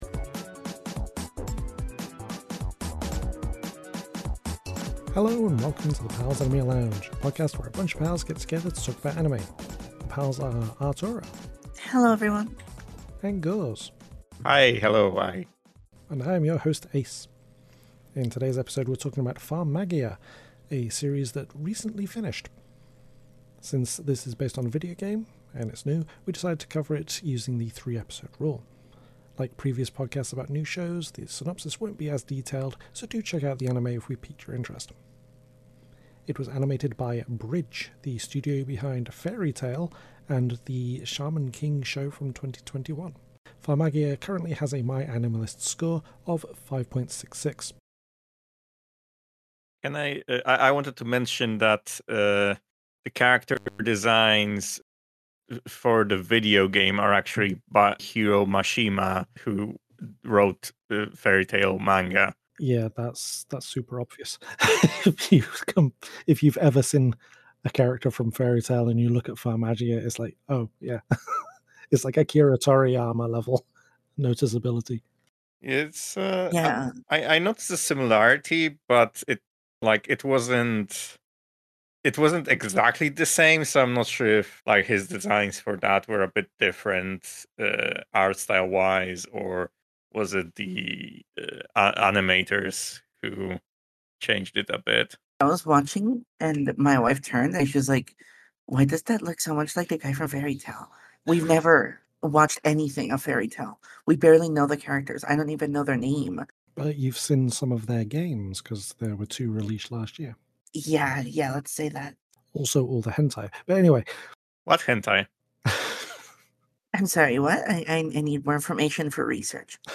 Welcome to the Pals Anime Lounge, a podcast where a bunch of pals get together to talk about anime!